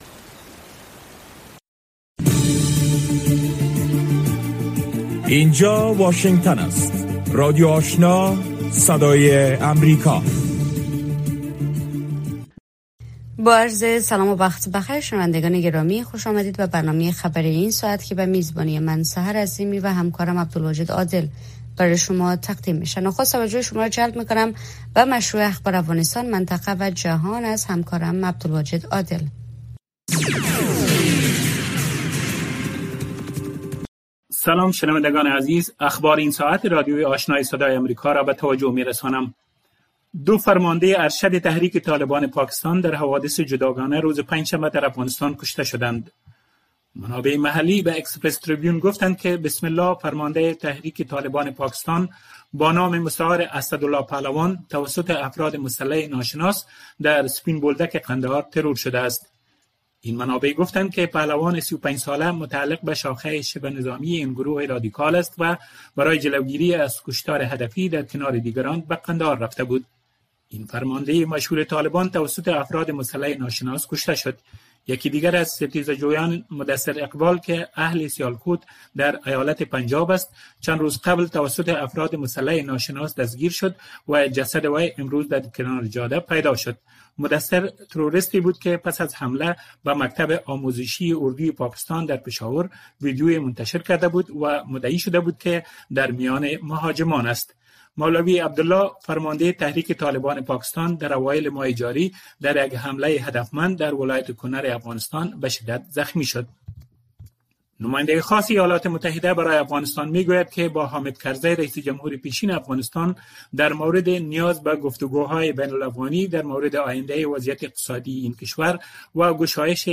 برنامۀ خبری شامگاهی